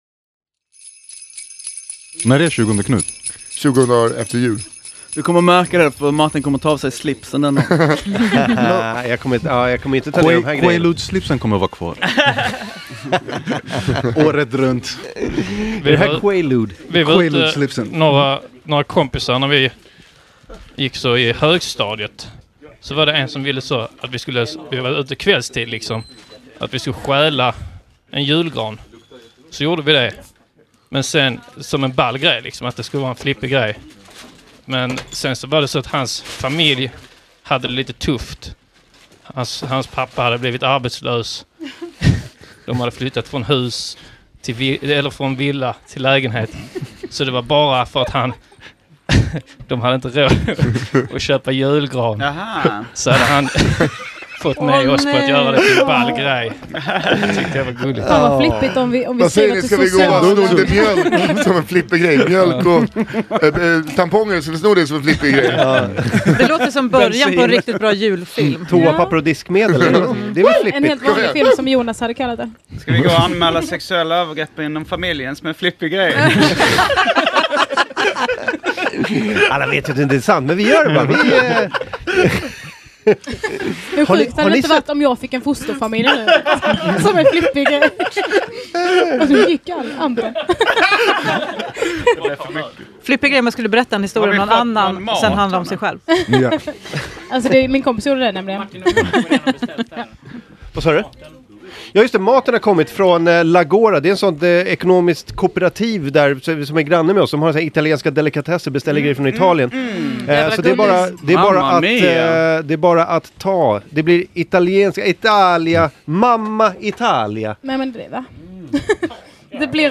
Downloads Download AMK_Morgon_Uppesittarkvall_Del_2.mp3 Content Här är AMK Morgons uppesittarkväll som spelades in i AMK Studios fredagen 15 december 2017.